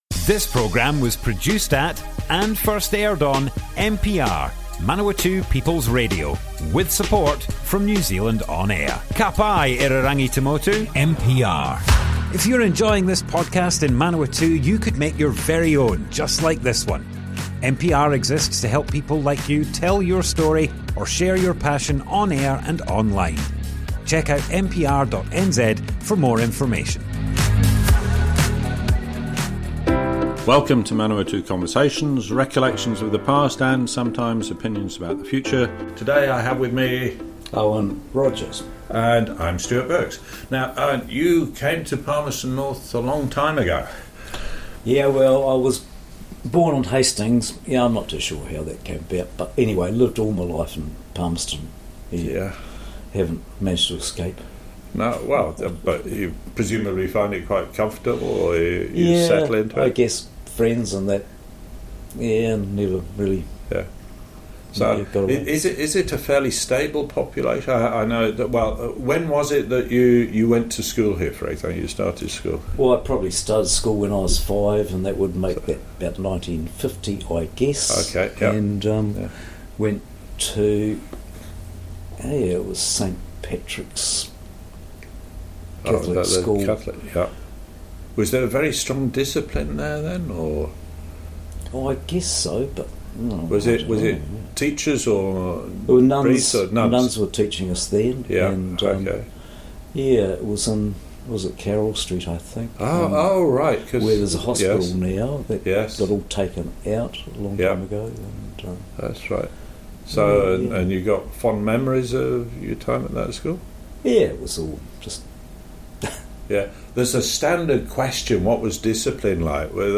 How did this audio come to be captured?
Manawatu Conversations More Info → Description Broadcast on Manawatu People’s Radio, 17th October 2023.